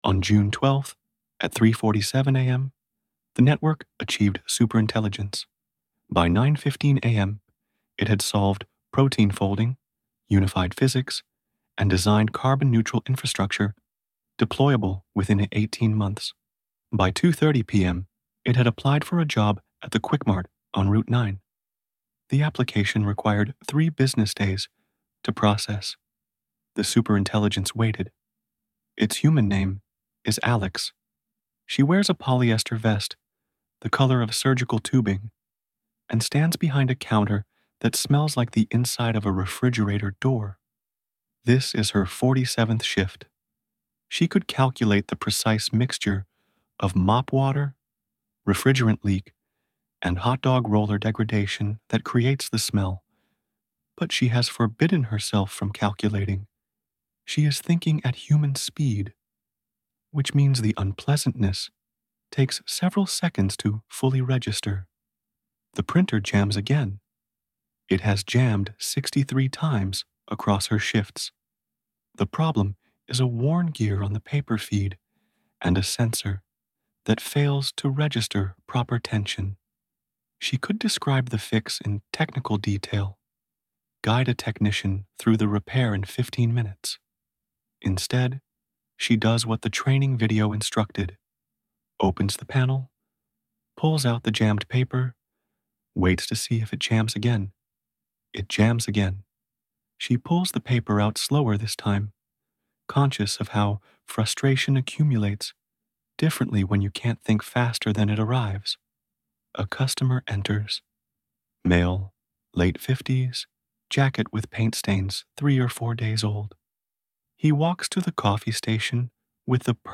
Narrated by Thomas (AI) via ElevenLabs